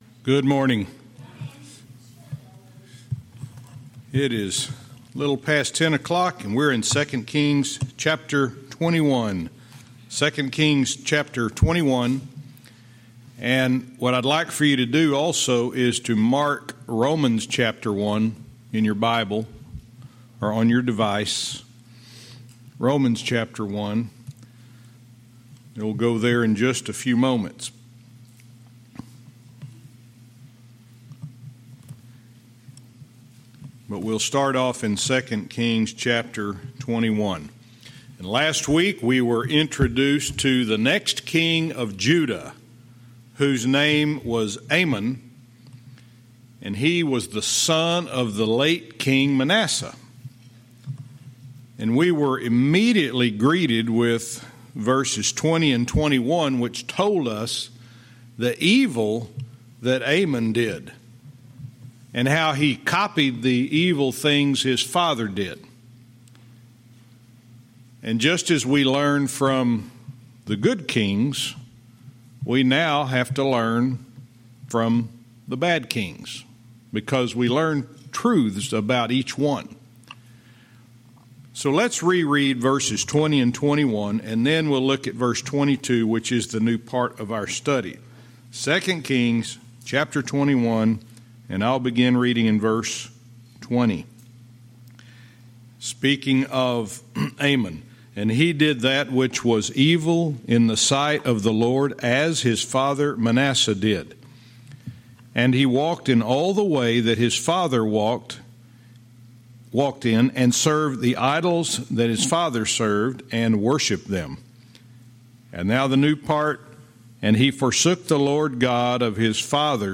Verse by verse teaching - 2 Kings 21:22-24